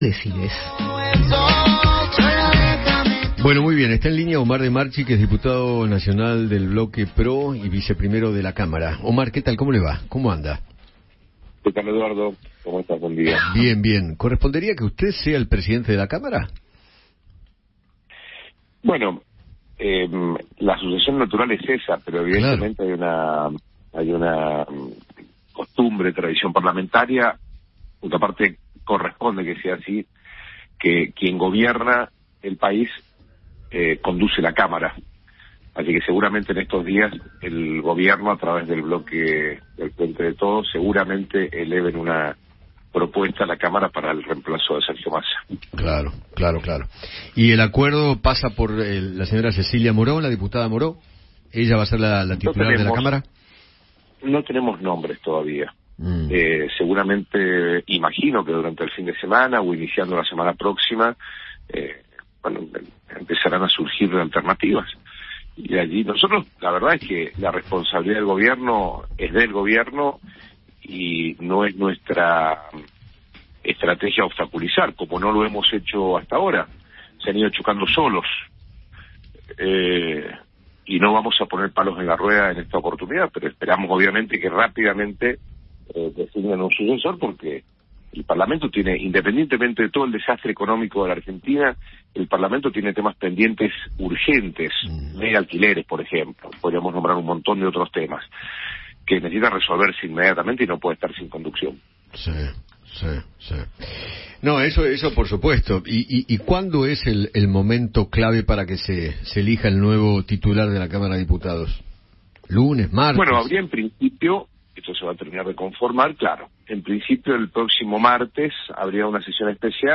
Omar De Marchi, diputado nacional de Juntos por el Cambio, habló con Eduardo Feinmann sobre el arribo de Sergio Massa al ministerio de Economía y se refirió a la sesión especial que hará la Cámara de Diputados para definir las autoridades.